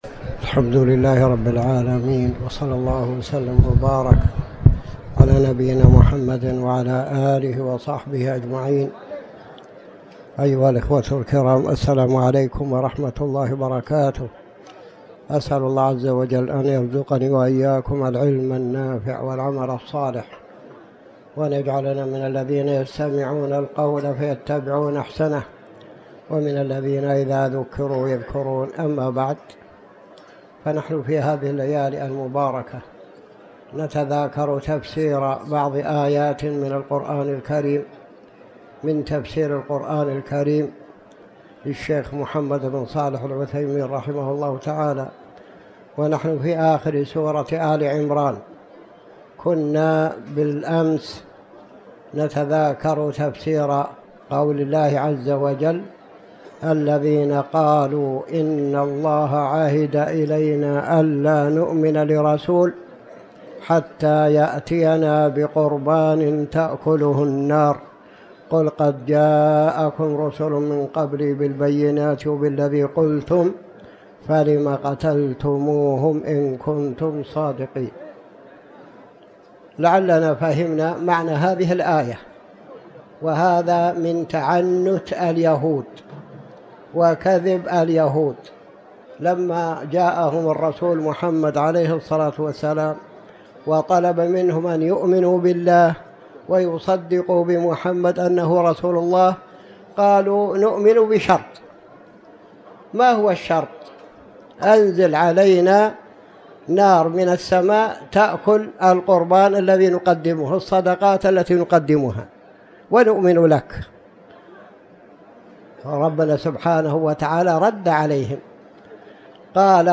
تاريخ النشر ١١ رجب ١٤٤٠ هـ المكان: المسجد الحرام الشيخ